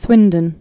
(swĭndən)